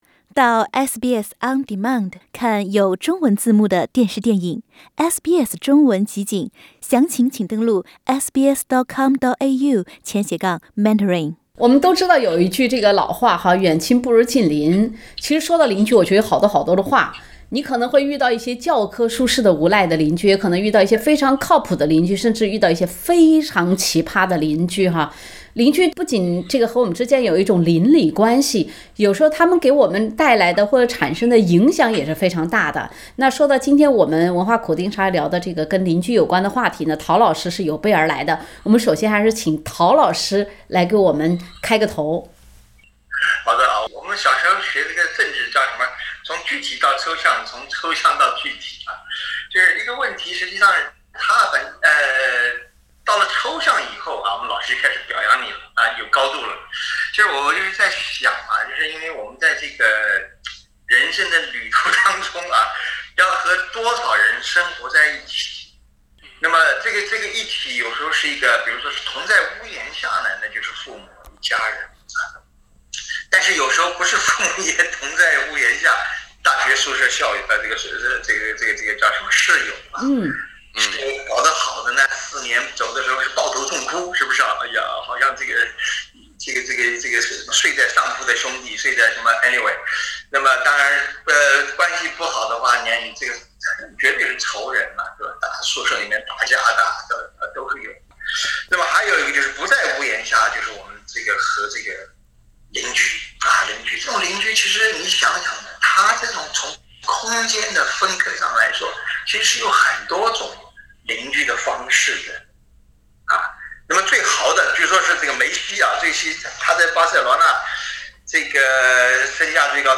在人生这个庞大的林子里，我们总归会遇到各种各样的人，各种各样的鸟，当然也可能是各种各样的“鸟人”。（点击封面图片，收听完整对话）
引得笑声一片。